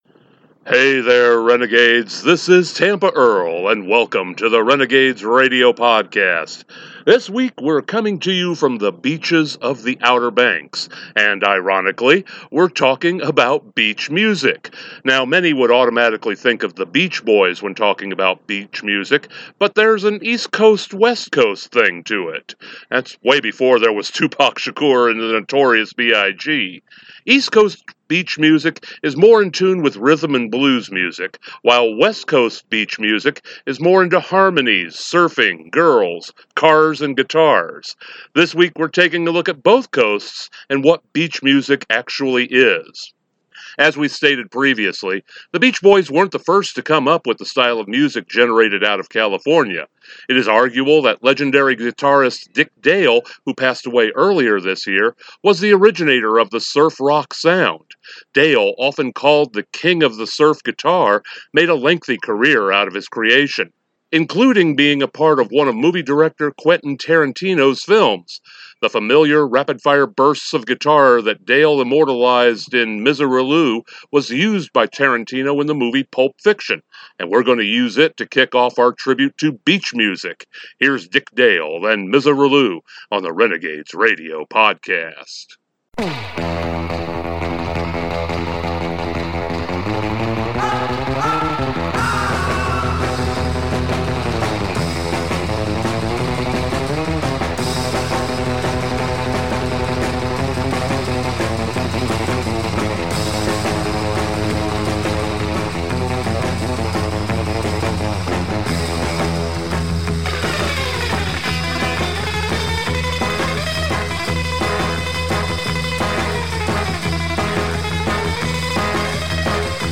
This week on the Renegades Radio Podcast, we’re coming to you from the Outer Banks of North Carolina!